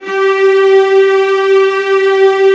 VIOLAS AN4-L.wav